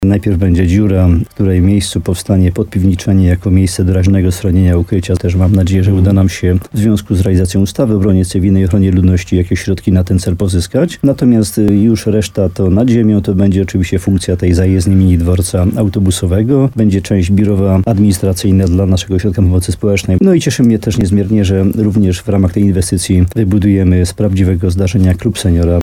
– Jesteśmy teraz na etapie przetargu, a budowę chcemy zrealizować w latach 2026-2027 – mówił w programie Słowo za Słowo w radiu RDN Nowy Sącz wójt gminy Łącko Jan Dziedzina.
Rozmowa z Janem Dziedziną: Tagi: schron stara zajezdnia dom senioram Nowy Sącz Słowo za Słowo budowa Łącko Jan Dziedzina HOT